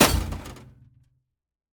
Minecraft Version Minecraft Version latest Latest Release | Latest Snapshot latest / assets / minecraft / sounds / block / vault / break2.ogg Compare With Compare With Latest Release | Latest Snapshot